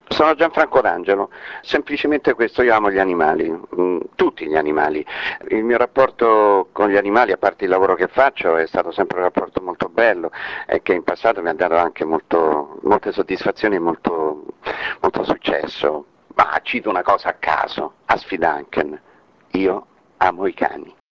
ASCOLTA GLI SPOT DI GIANFRANCO D'ANGELO